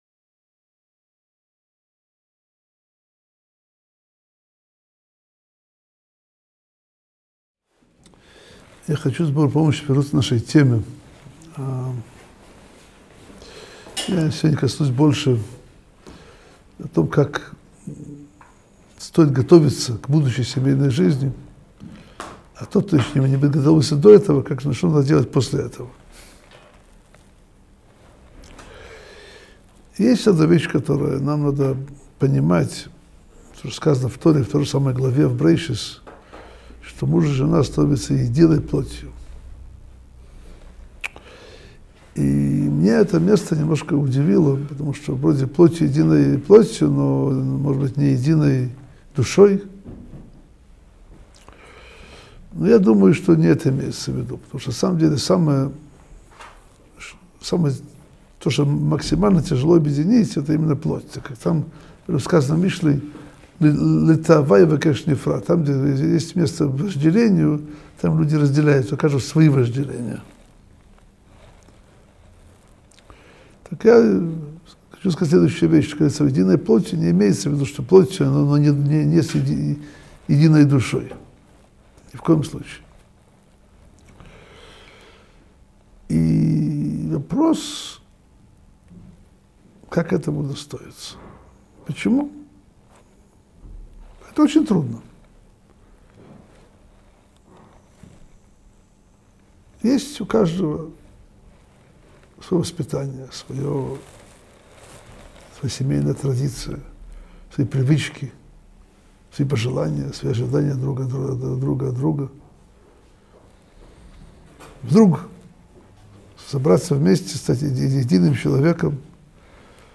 Урок № 4.